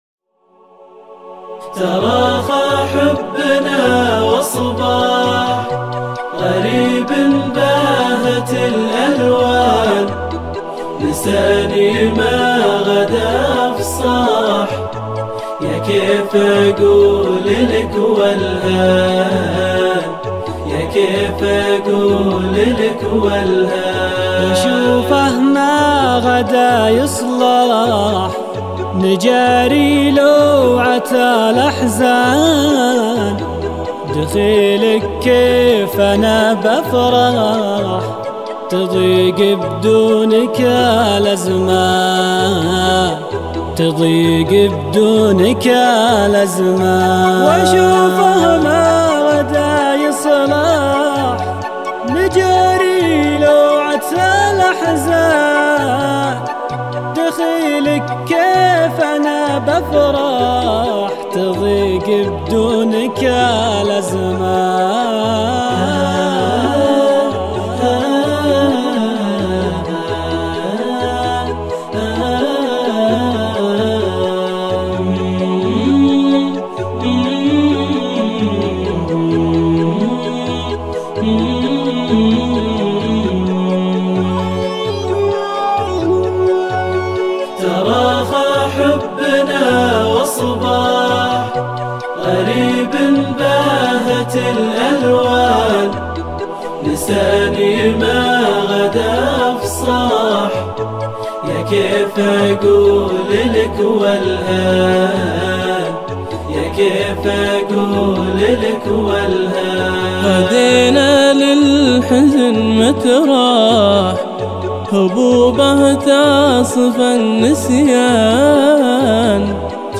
انشودة